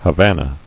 [Ha·van·a]